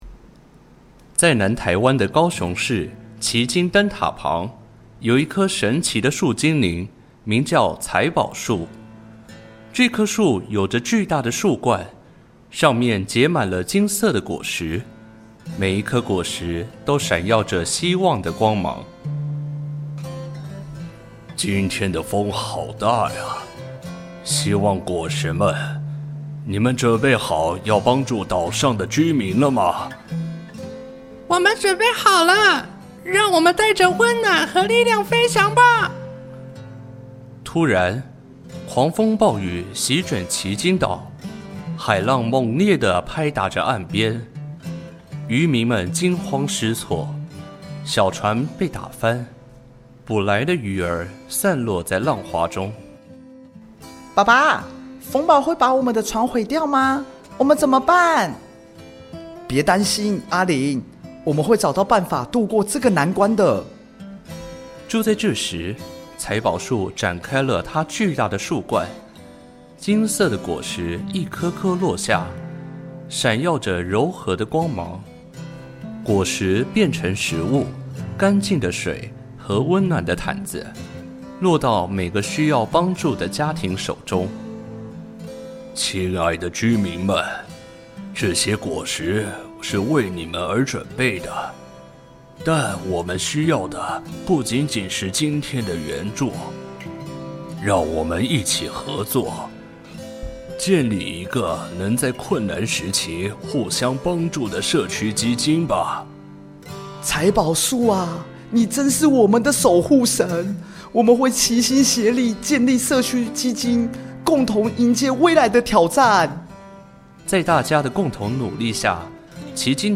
故事有聲書